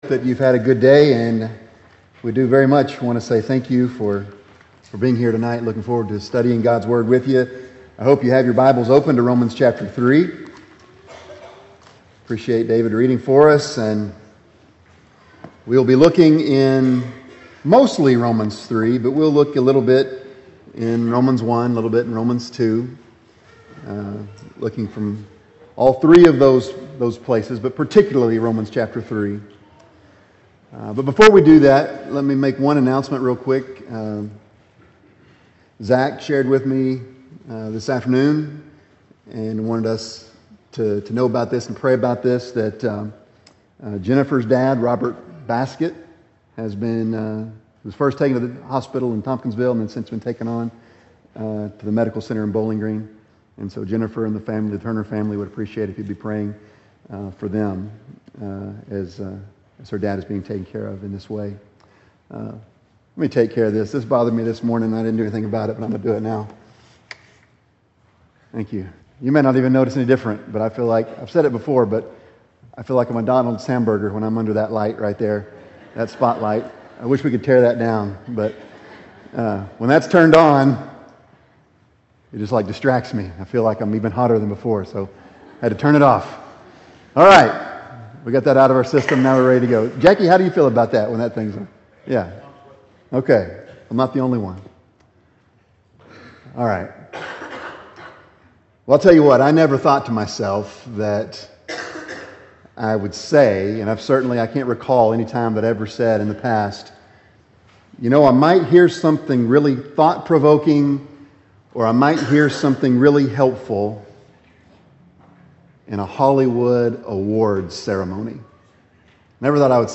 You are NOT Perfect – South Green Street church of Christ